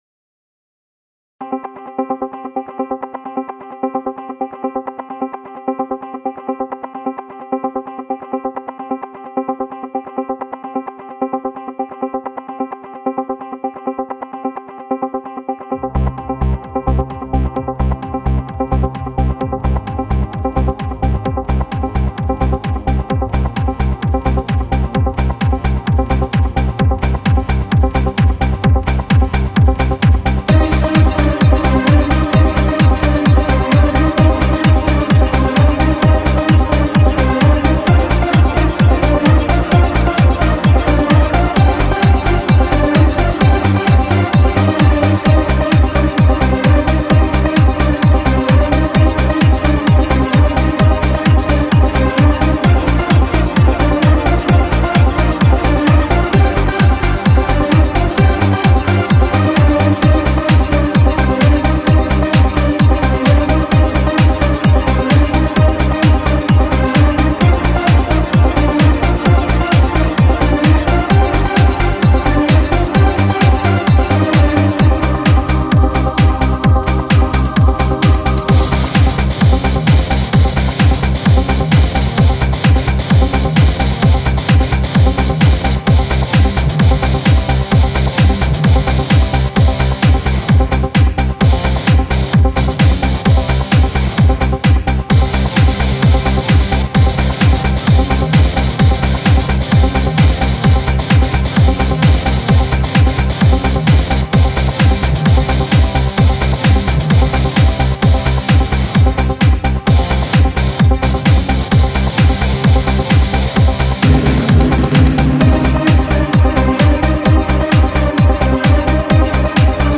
club - danse - action